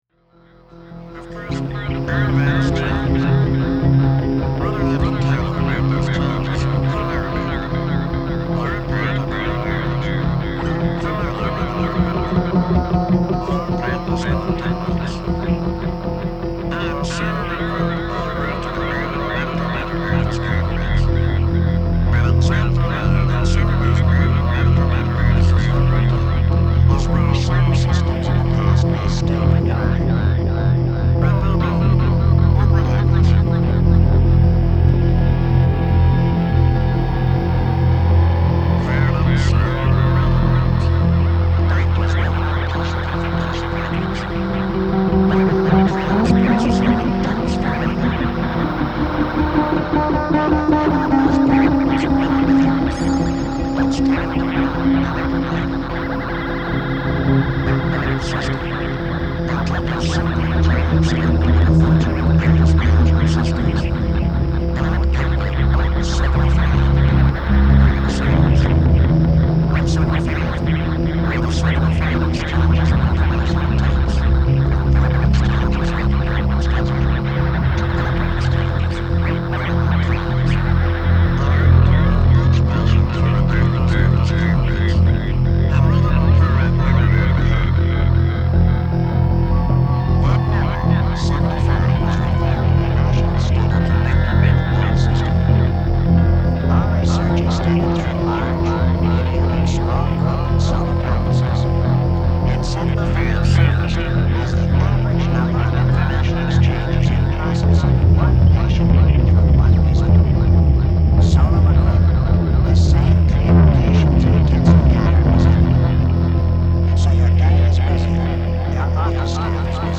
A sales seminar cassette processed through ring modulator and echo, on a bed of bass.